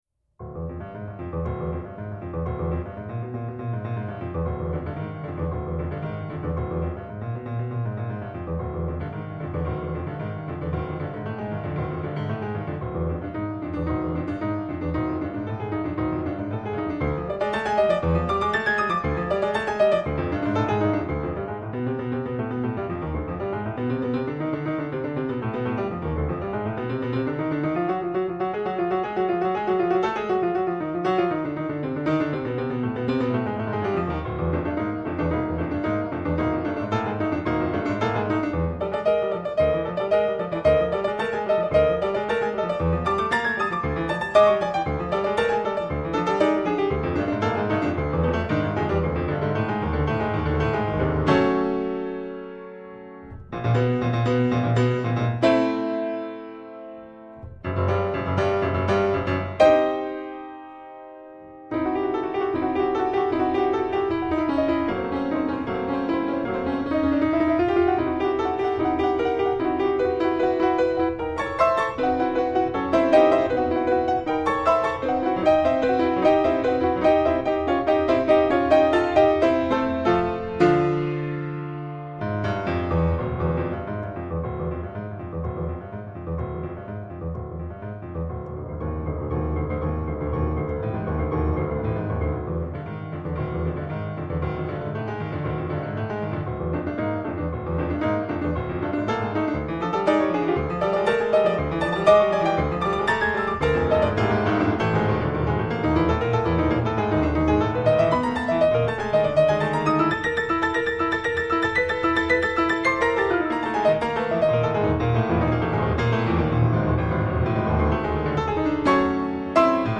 Allegro molto Harmonika